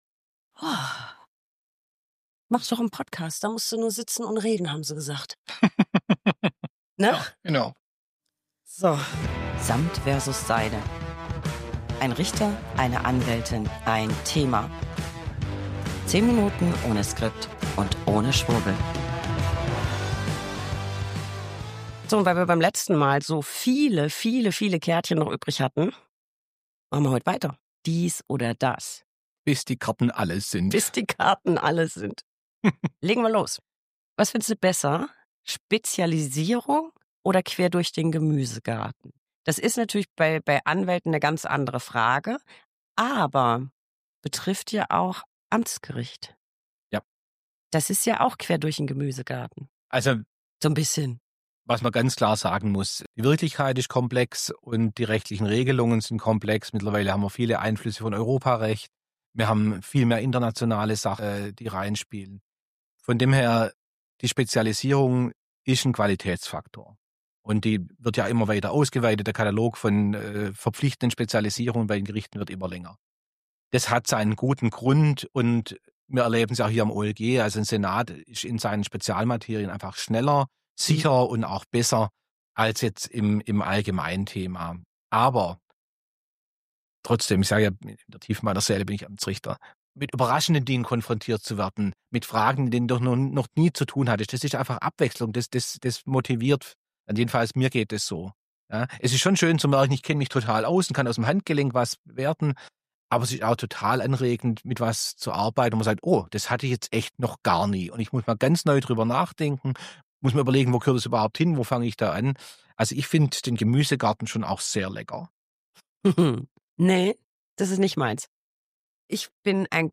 1 Anwältin + 1 Richter + 1 Thema. 10, äh 20, Minuten ohne Skript und ohne Schwurbel.